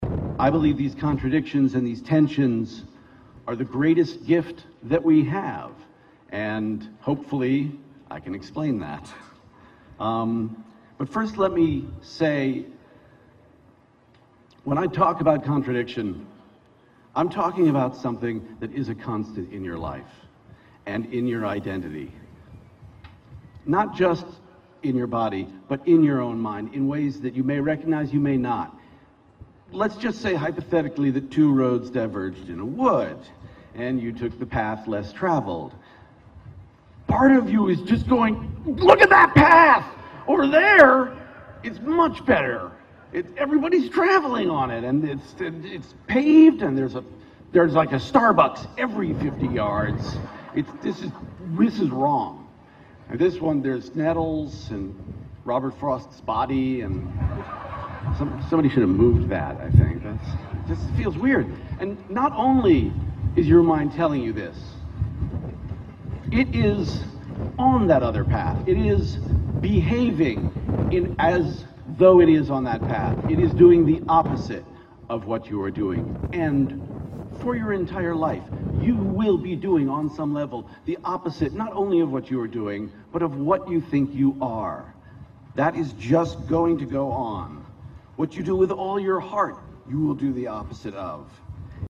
公众人物毕业演讲 第243期:乔斯韦登2013卫斯理大学(3) 听力文件下载—在线英语听力室